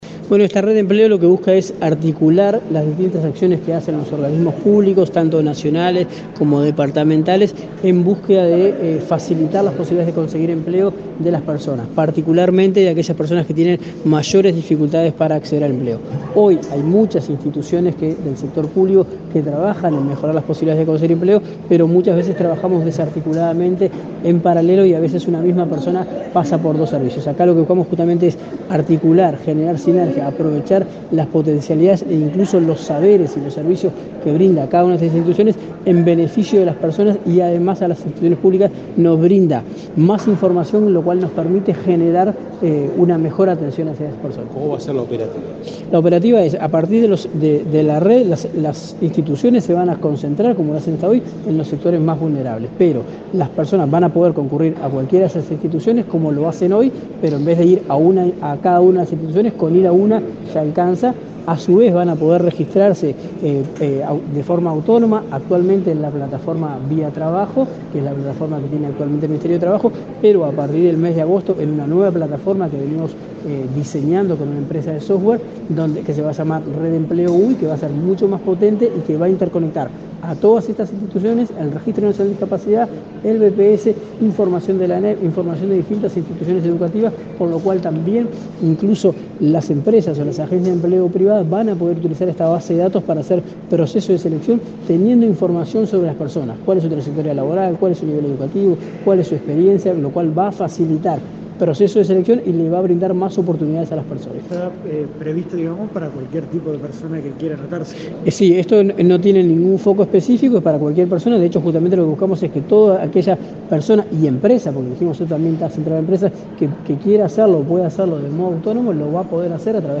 Declaraciones del subsecretario de Trabajo, Daniel Pérez
El subsecretario de Trabajo, Daniel Pérez, dialogó con la prensa, luego de participar, este viernes 24 en la Torre Ejecutiva, en la presentación del